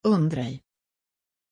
Pronuncia di Ondrej
pronunciation-ondrej-sv.mp3